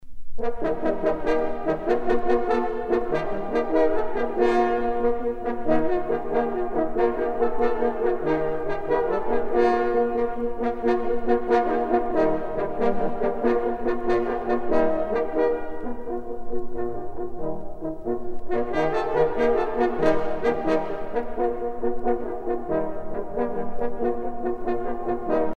trompe - fanfare
circonstance : vénerie
Pièce musicale éditée